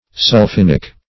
Search Result for " sulphinic" : The Collaborative International Dictionary of English v.0.48: Sulphinic \Sul*phin"ic\, a. (Chem.)
sulphinic.mp3